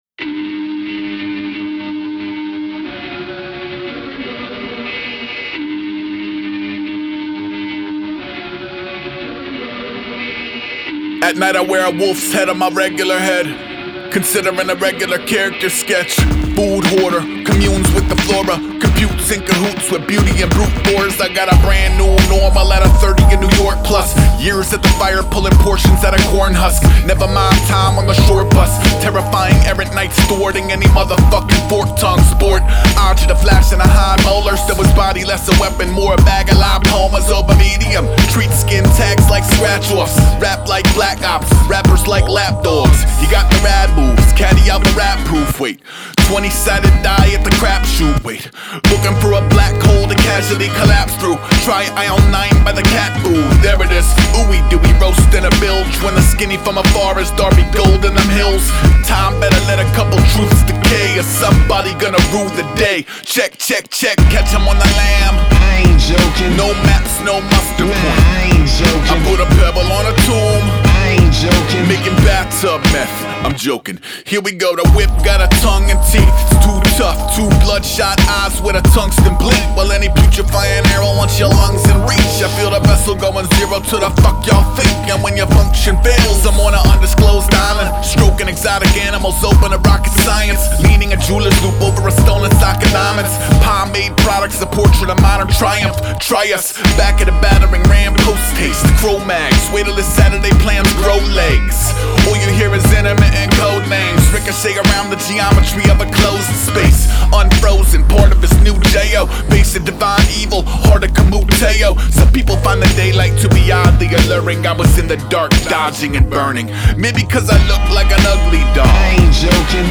Жанр: Rap/Hip-Hop.